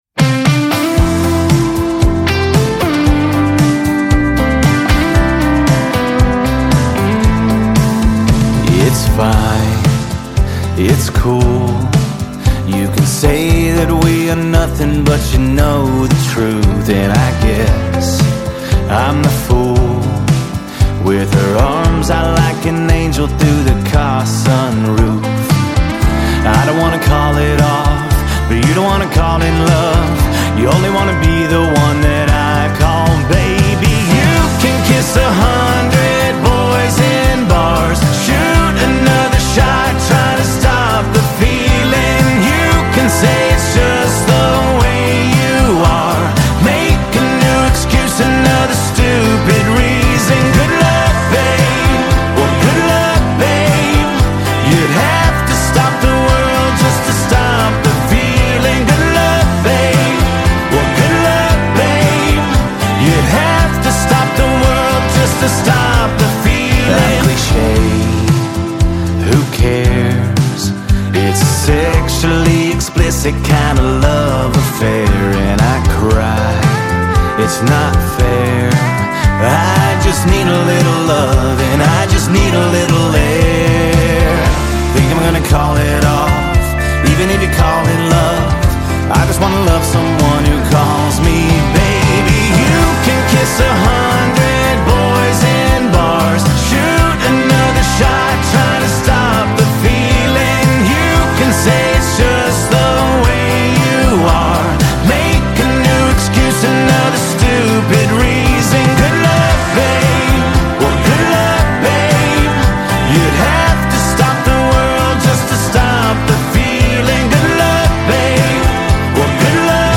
Жанр: Country